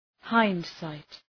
Προφορά
{‘haınd,saıt}